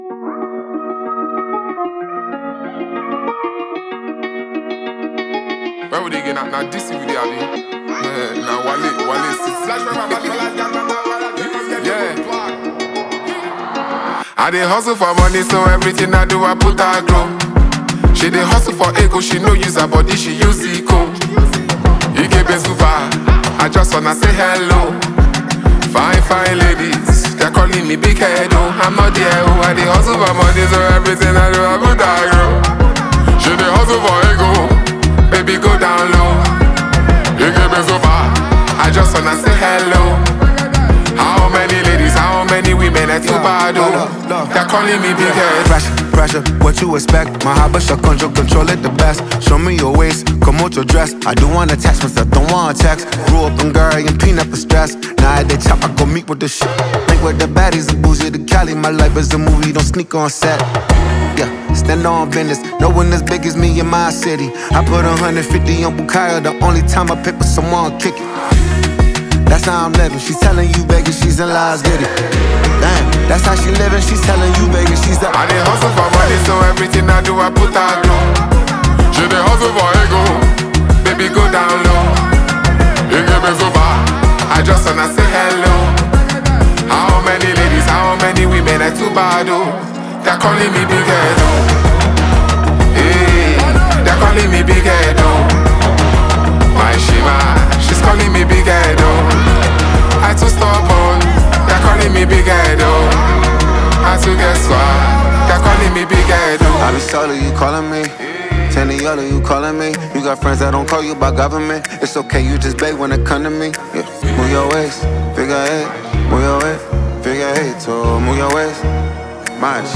smooth but confident delivery
powerful, gritty signature touch